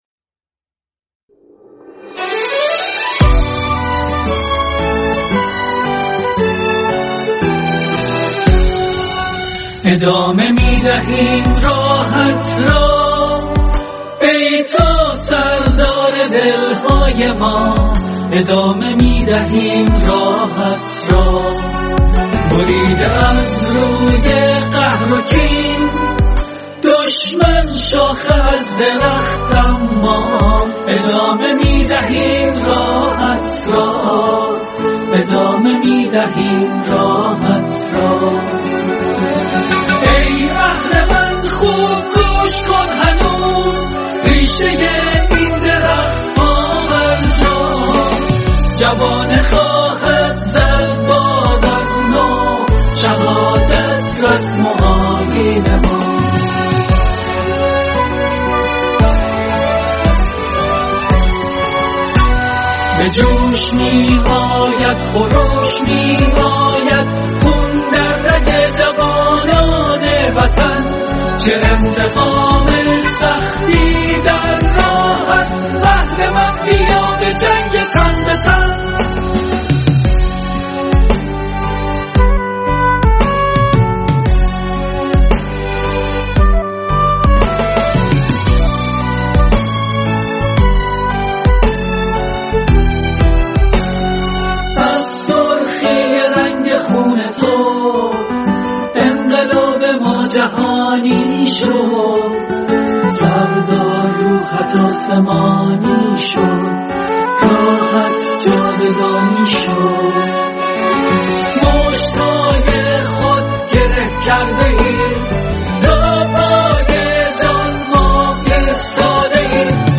ترانه ی پاپ
همراه با صدای سردار قاسم سلیمانی